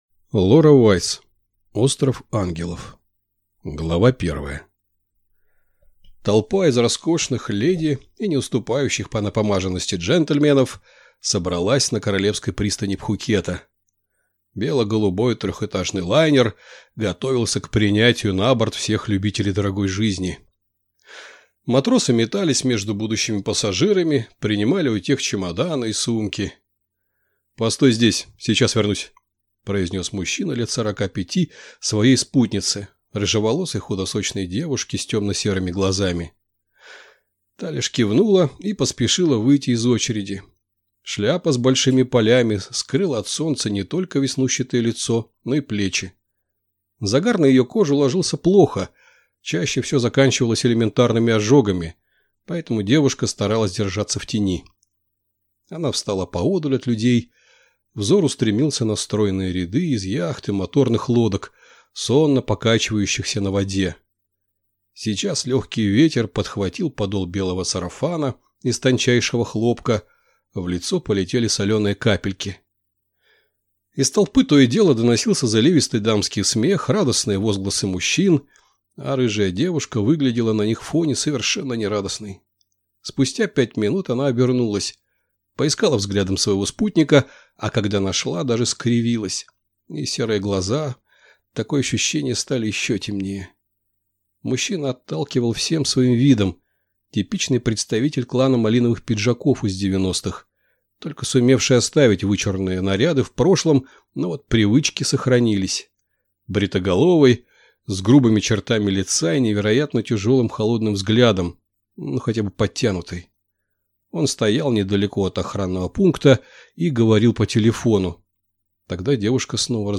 Аудиокнига Остров Ангелов | Библиотека аудиокниг